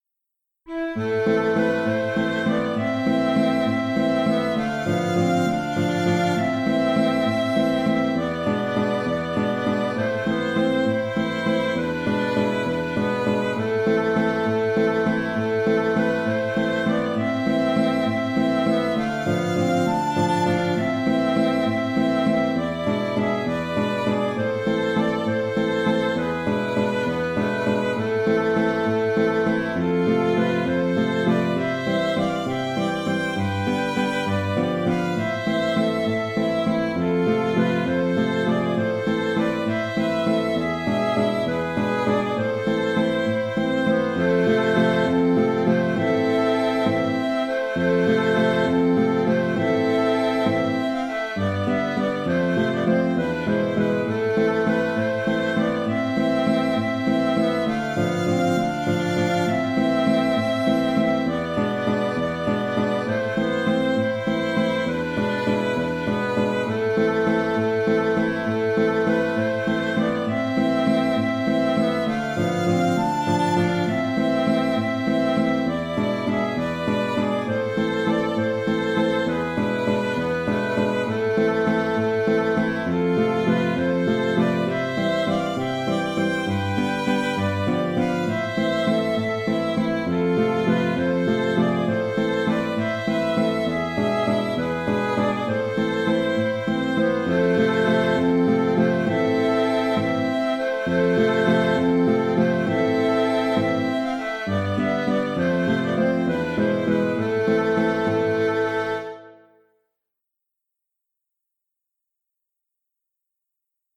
Moldau (La) (Arrangement en valse) - Autres musiques
J’en ai fait une valse qui entraine facilement les danseurs sur la piste.
Attention de ne pas faire trainer la mélodie.